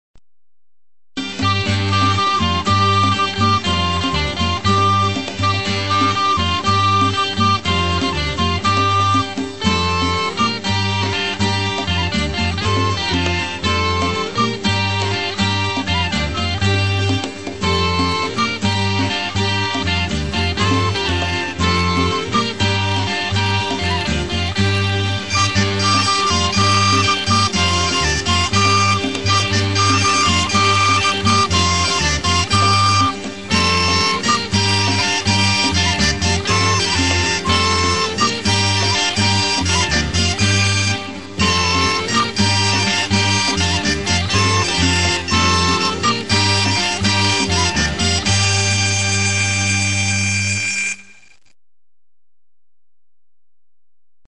Bransle.wma